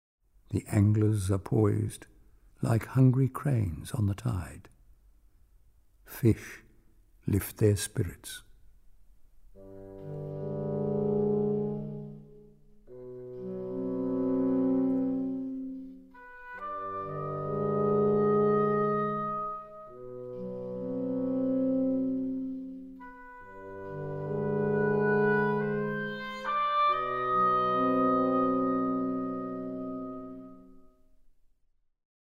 Studio 420, Ferry Road, Brisbane, 6 – 8 February 2012